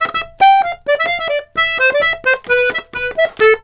Index of /90_sSampleCDs/USB Soundscan vol.40 - Complete Accordions [AKAI] 1CD/Partition C/04-130POLKA
S130POLKA3-R.wav